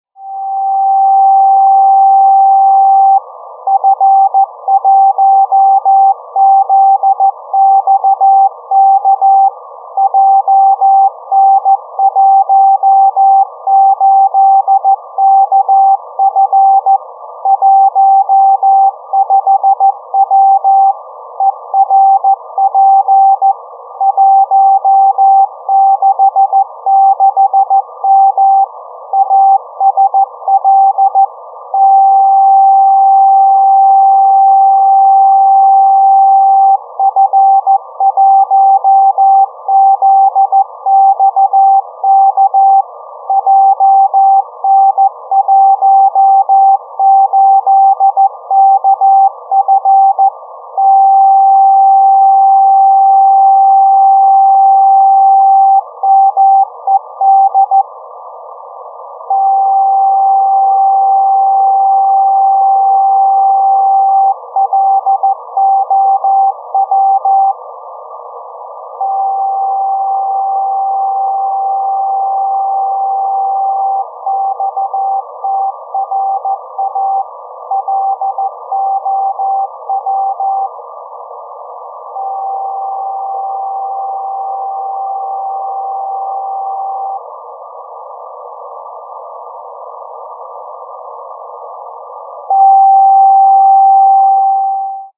Modulation : A1A
Particularité de la balise : elle émet en deuxième partie de son message trois paliers de puissances de puissances décroissantes.
Message (indicatif, locator, pwr...) en puissance “nominale” 30W  durant 1minute environ
Puis trois tunes de 5 secondes précédés des indications suivantes :
"medium" puis tune de 5s à 9,5W (-5dB)
"low" puis tune de 5s à 3,0W (-10dB)
"xtra low" puis tune de 5s à 1,3W (-14dB)